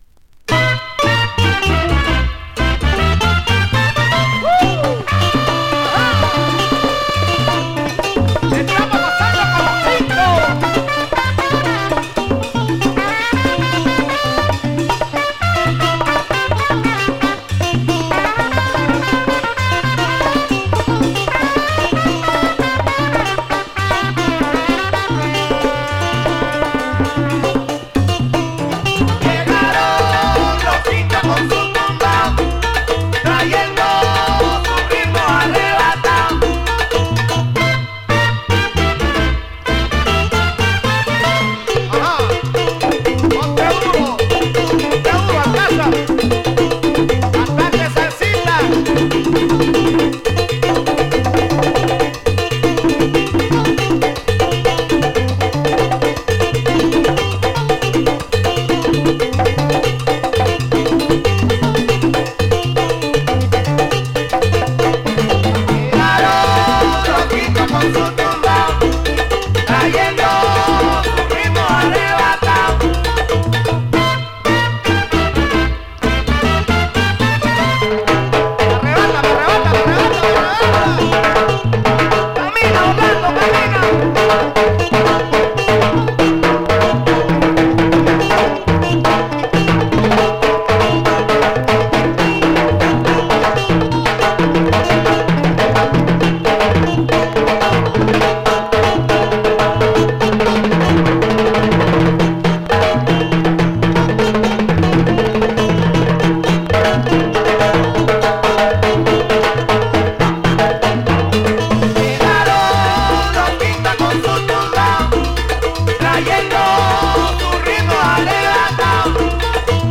very gritty hard-edged sounds from South America